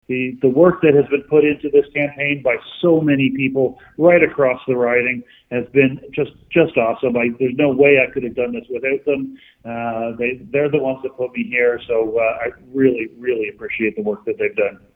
Bresee was in attendance of an election night party at Loyalist Golf & Country Club in Bath, where he is receiving much congratulations and is expressing gratitude.